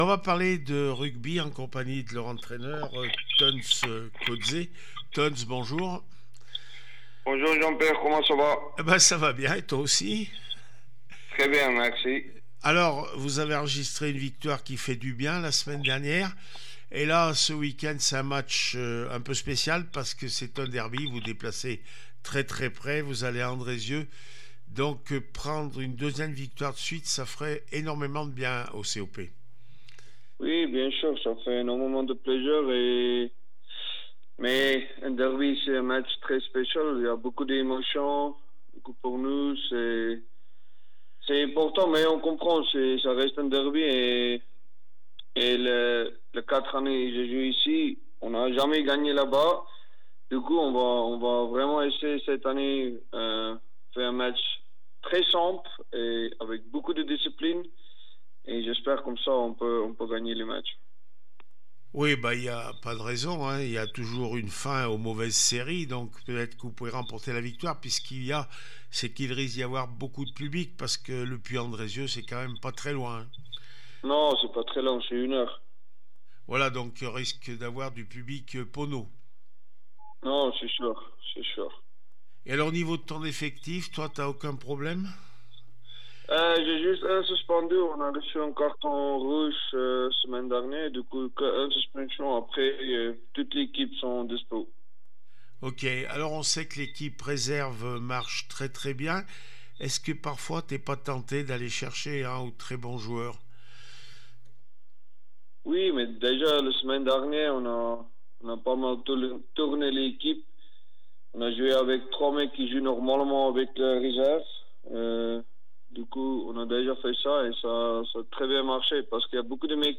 22 novembre 2024   1 - Sport, 1 - Vos interviews